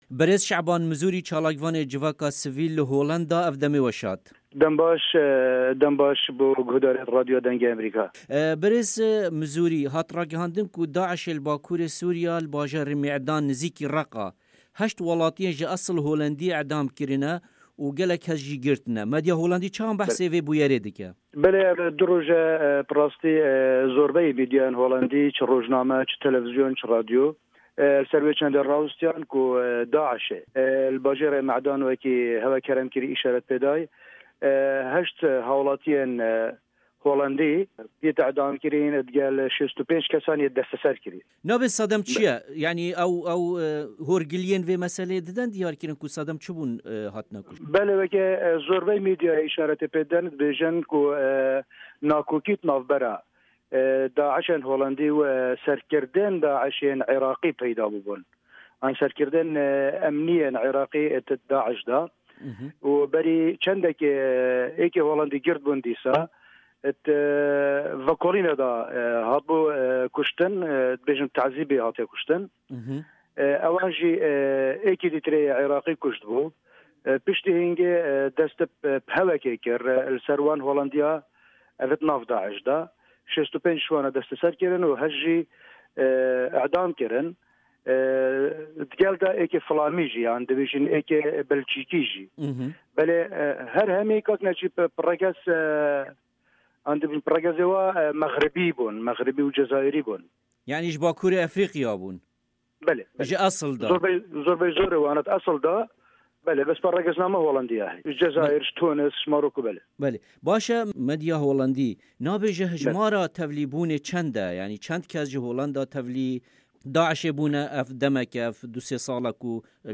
Hevpeyin